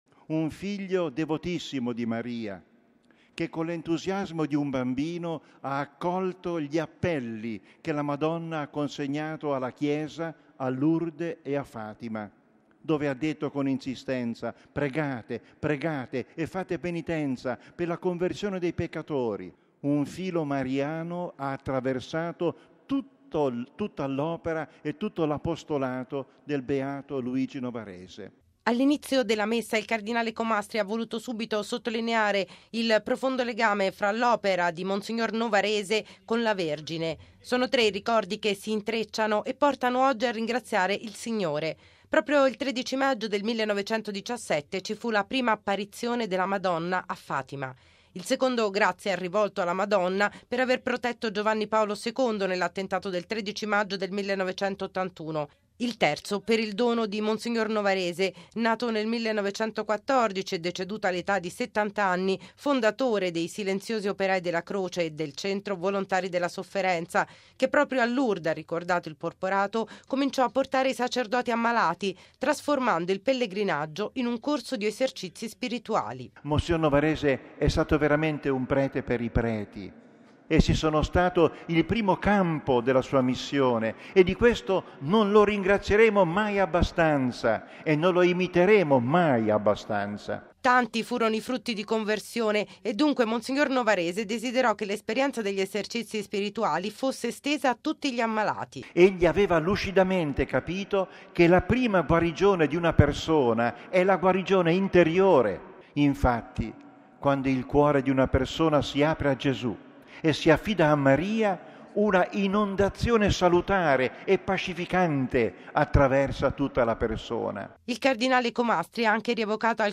◊   Un filo mariano ha attraversato l’opera di mons. Luigi Novarese, l’apostolo dei malati. Lo ha sottolineato il cardinale Angelo Comastri, arciprete della Basilica Vaticana, dove è stata celebrata stamani la Messa, in occasione della Beatificazione di mons. Novarese, avvenuta sabato scorso. Mons. Novarese volle che l’esperienza degli esercizi spirituali fosse estesa a tutti i malati, ha rilevato il porporato nell’omelia.